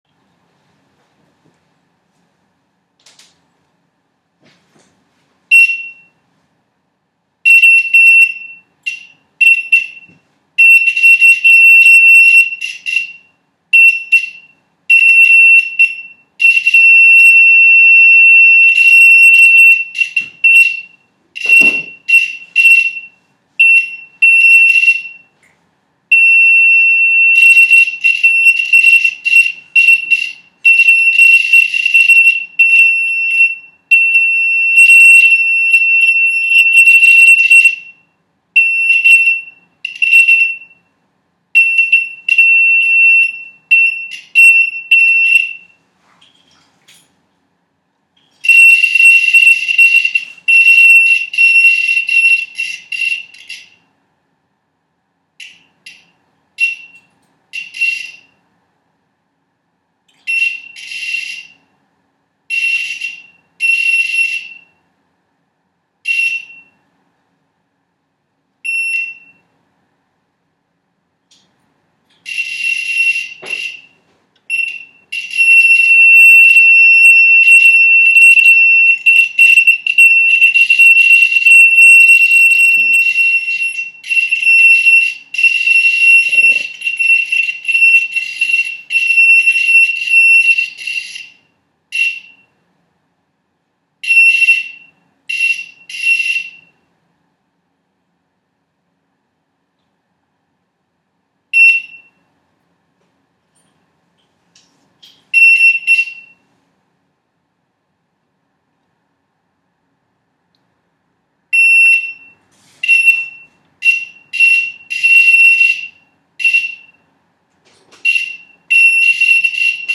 Also meine Signalfrequenz beträgt 48kHz und die FFT habe ich ja schon gepostet.
Bsp. audiodatei mit piepton
Der Ton (ich nehme an es geht um das Piepen) wird doch immer mal wieder kurz unterbrochen.
Ebenso besteht der Ton nicht nur aus einer Frequenz.